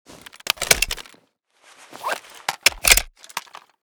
AR_reload.ogg.bak